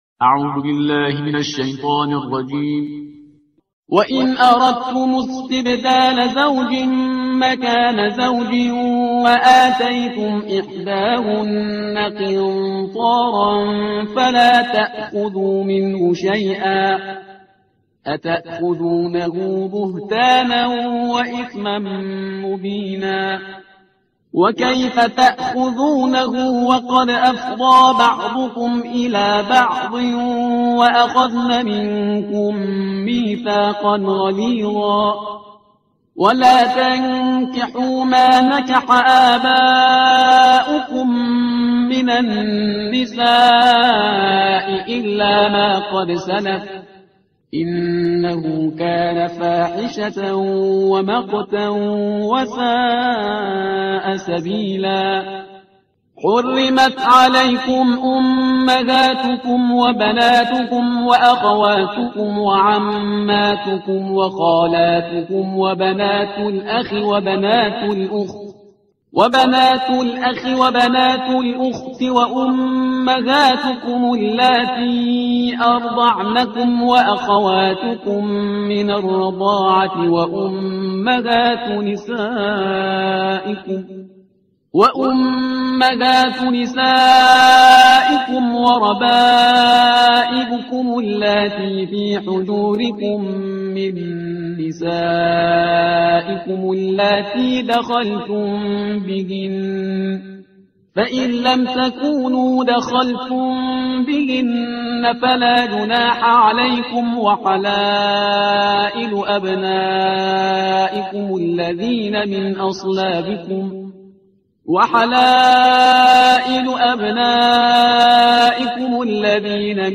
ترتیل صفحه 81 قرآن با صدای شهریار پرهیزگار
ترتیل صفحه 81 قرآن با صدای شهریار پرهیزگار ترتیل صفحه 81 قرآن – جزء چهارم